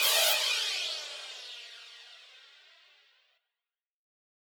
Crashes & Cymbals
MB Crash (8).wav